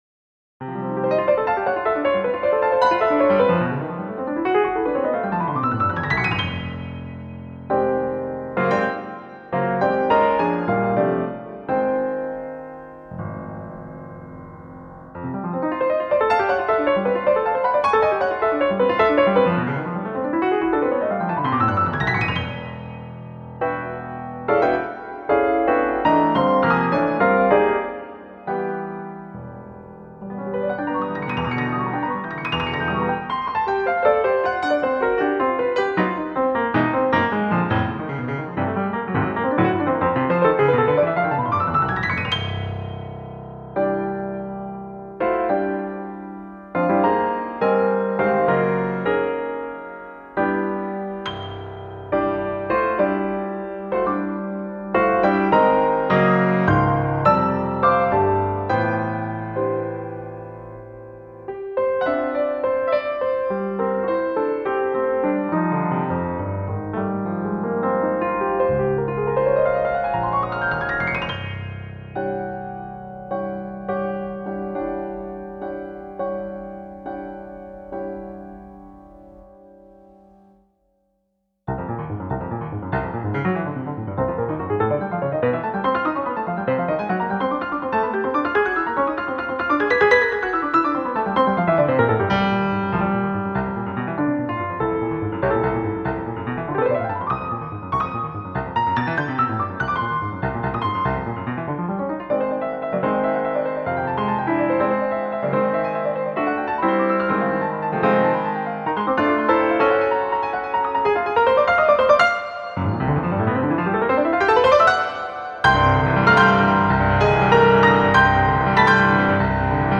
SCORING piano
piano.
is a brisk and colorful ride